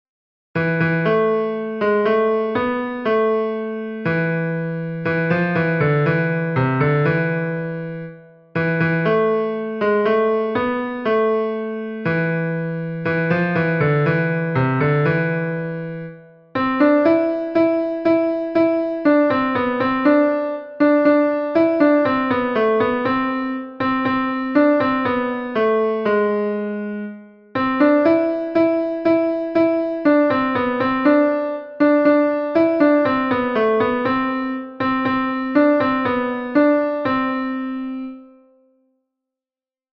Ténors 1